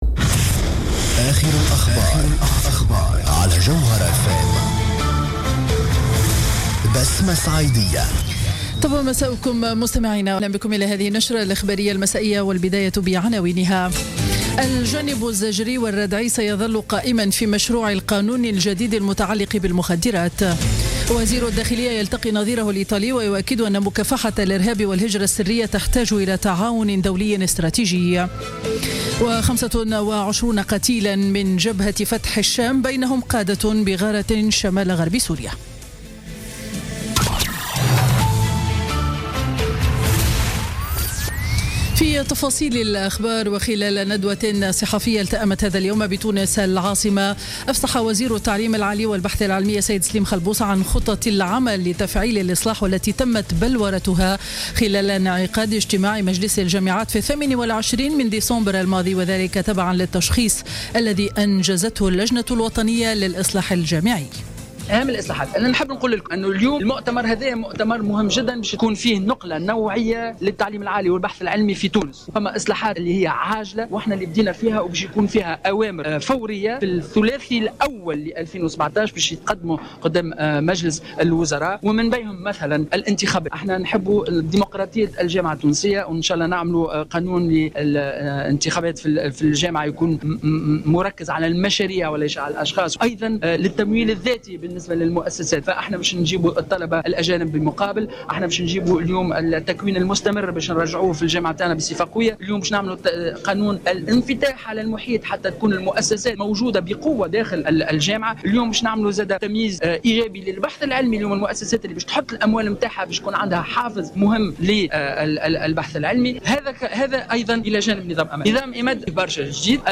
نشرة أخبار السابعة مساء ليوم الثلاثاء 3 جانفي 2017